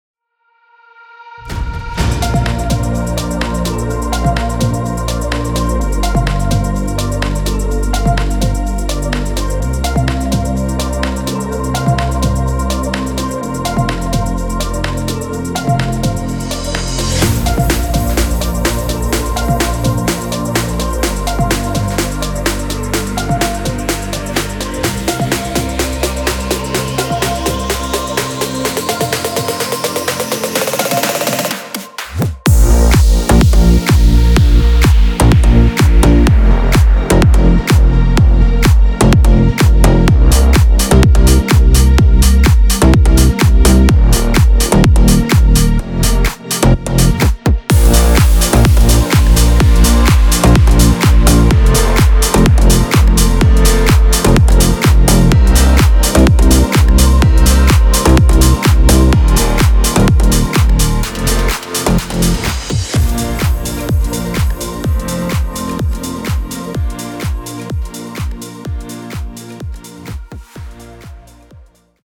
Popschlagersong im aktuellen Style.
Hier kannst du kurz ins Playback reinhören.
BPM – 126
Tonart – Bb-major